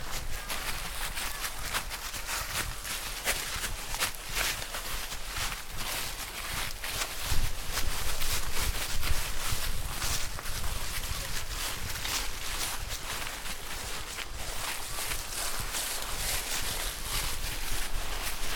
낙엽.mp3